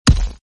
ThudTR.ogg